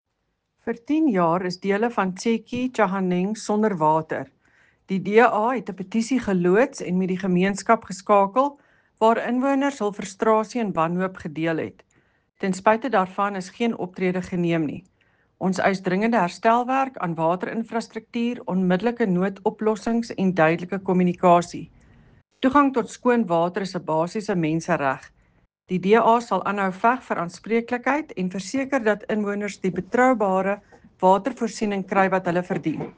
Afrikaans soundbite by Cllr Eleanor Quinta.